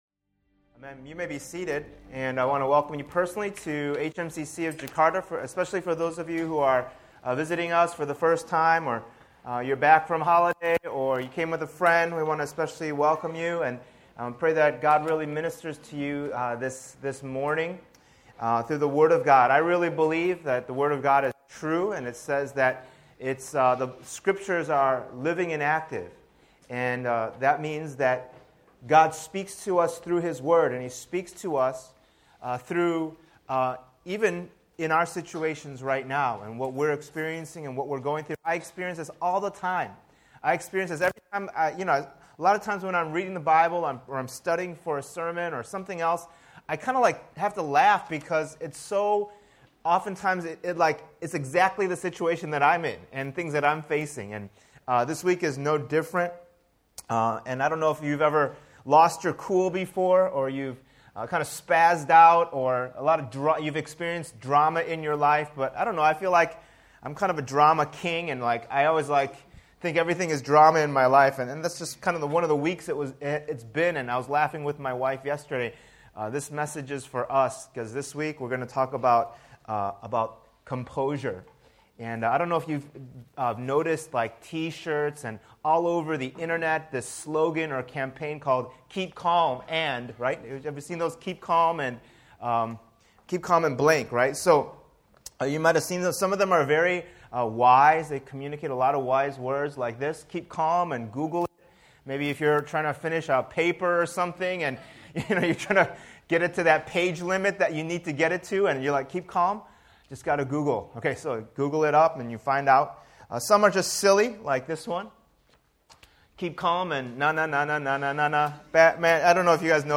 Download Audio Subscribe to Podcast Audio The Kingdom Series This sermon series called “The Kingdom” will go through the books of 1 and 2 Samuel.